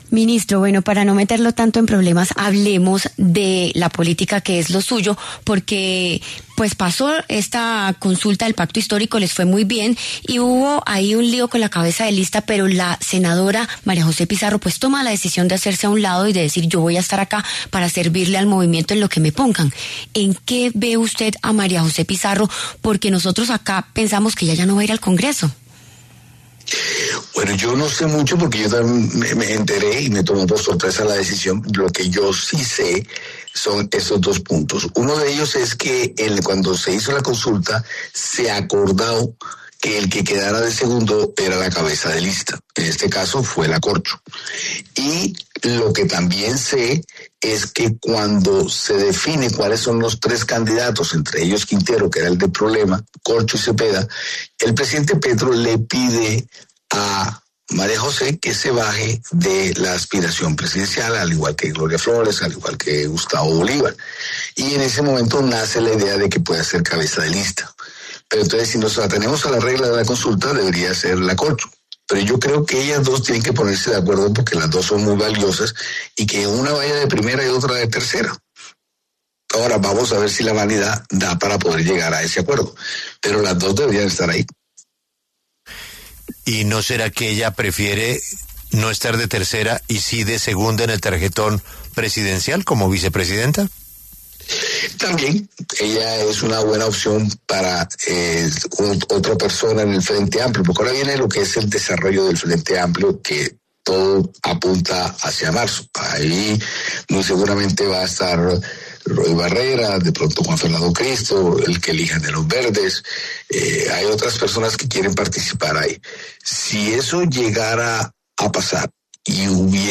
Al respecto, en La W, con Julio Sánchez Cristo, se pronunció el ministro del Interior, Armando Benedetti, quien dijo que la actual senadora María José Pizarro sería una buena candidata a vicepresidenta.